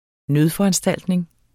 Udtale [ ˈnøð- ]